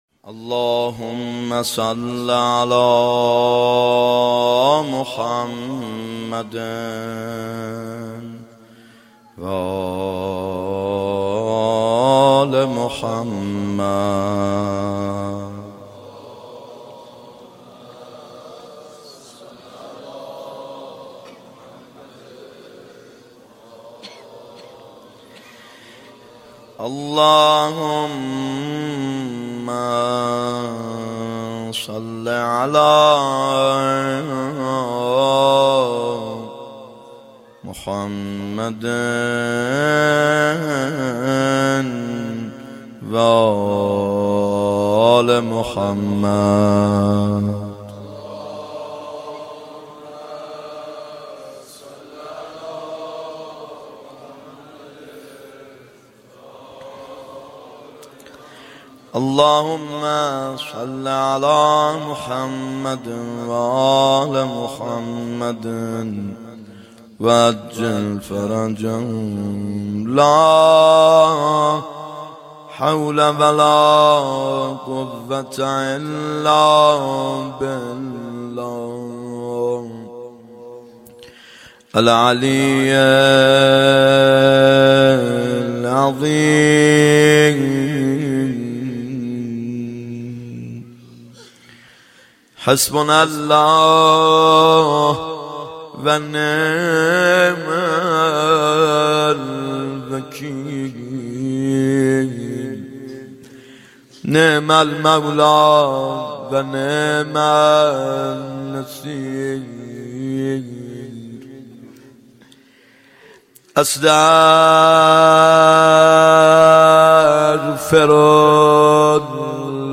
محرم 91 ( هیأت یامهدی عج)